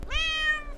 Cat Meow Sound Effect Free Download
Cat Meow